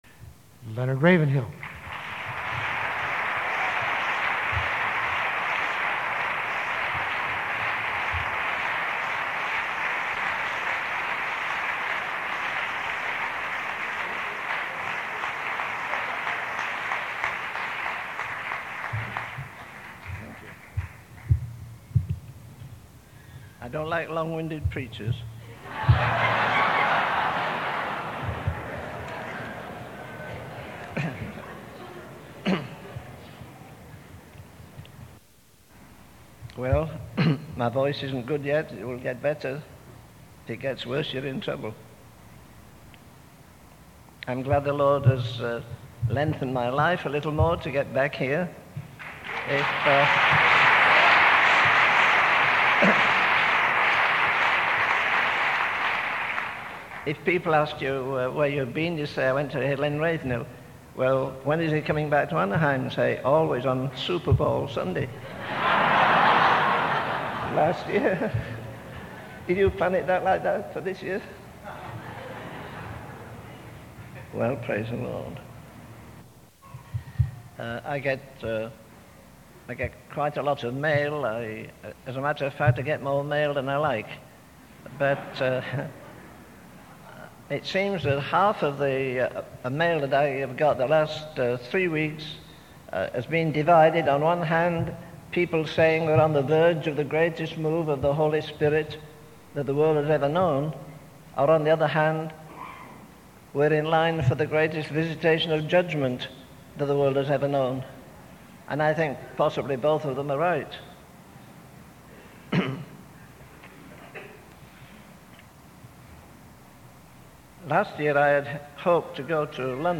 In this sermon, the speaker highlights the excessive focus on entertainment and pleasure in today's society. He emphasizes the negative impact of television, which he believes is designed to distract people from thinking critically. The speaker also references the decline of the Roman Empire, attributing it to factors such as divorce, high taxes, and a craze for pleasure.